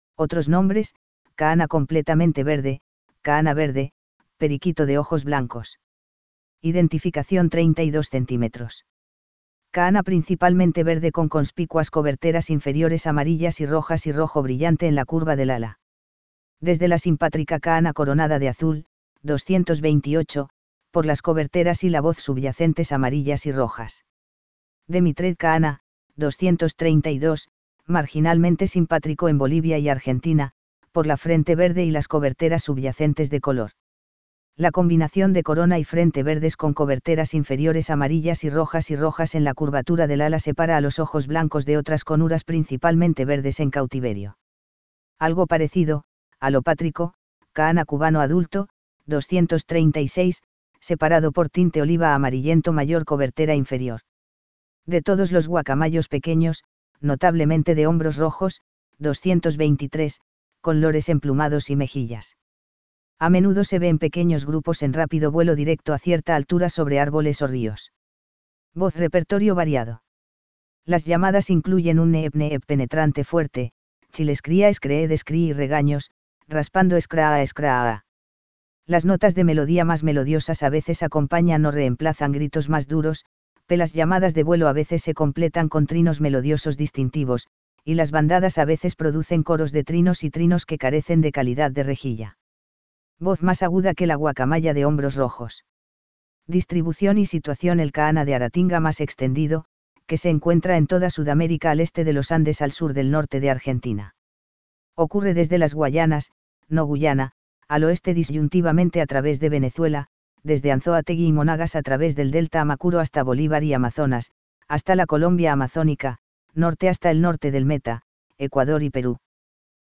VOZ Repertorio variado. Las llamadas incluyen un neeep neeep penetrante fuerte, chill-scree-ah screed scree y regaños, raspando scraaah scraaah.
Voz más aguda que la guacamaya de hombros rojos.